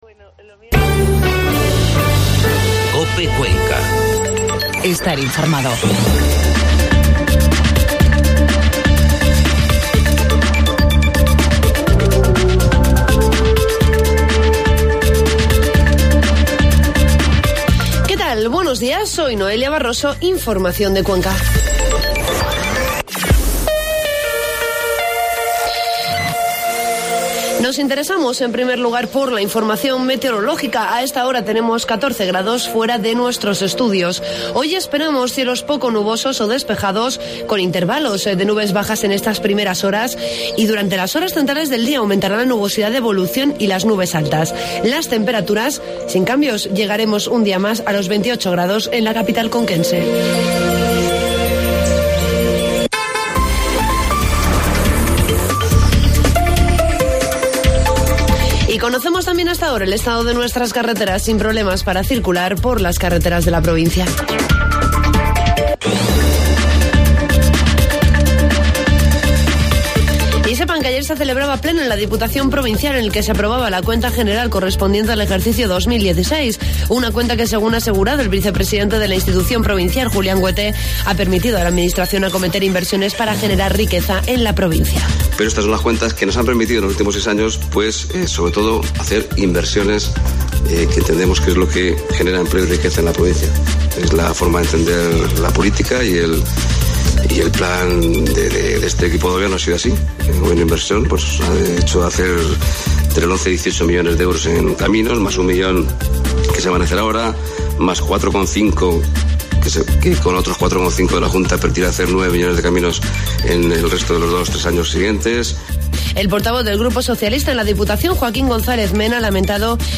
Informativo matinal COPE Cuenca 28 de septiembre